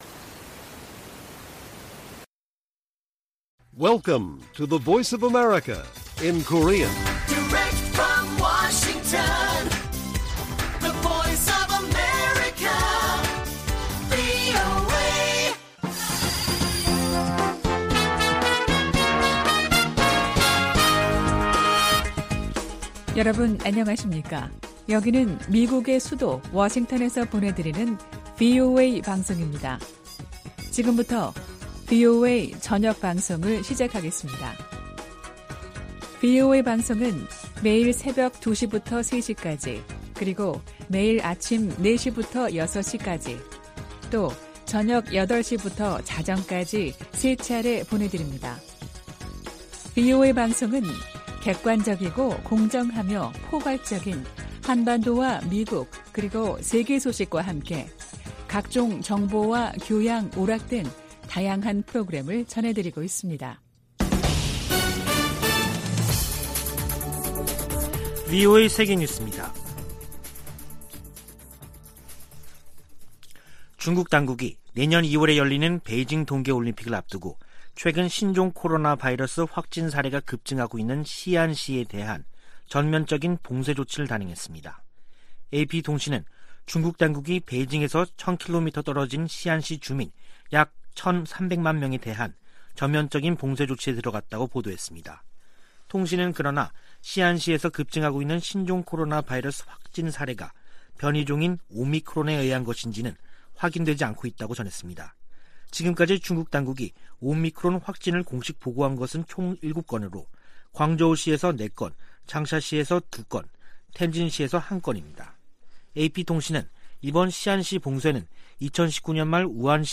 VOA 한국어 간판 뉴스 프로그램 '뉴스 투데이', 2021년 12월 23일 1부 방송입니다. 미 의회가 올해 처리한 한반도 외교안보 관련안건은 단 한 건이며, 나머지는 내년으로 이월될 예정입니다. 지난 2016년 김정은 국무위원장과 함께 미국의 인권 제재 대상으로 지정됐던 김경옥 전 노동당 조직지도부 제 1부부장이 연방 관보에 다시 이름을 올렸습니다. 올해 국제사회의 대북 지원 모금 실적이 지난 10년 새 최저 수준으로 나타났습니다.